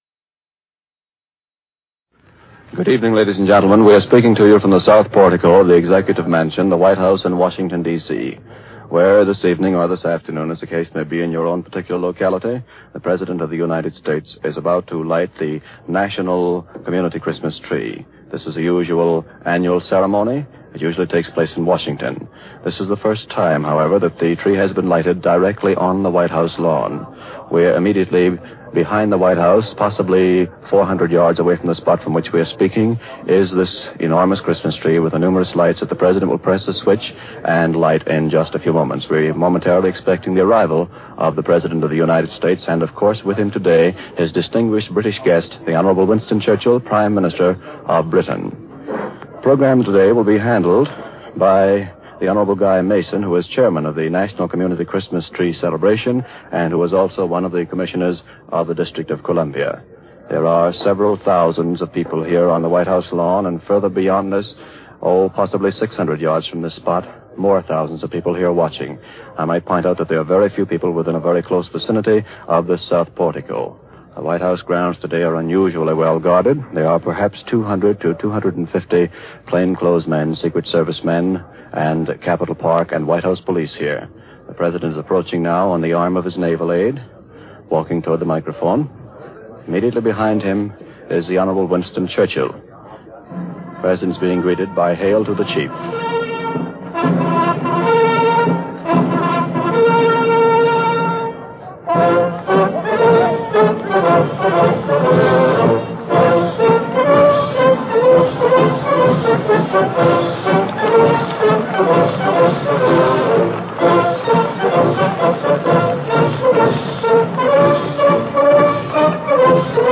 OTR Christmas Shows - White House Christmas Tree Ceremony - 1941-12-24 MBS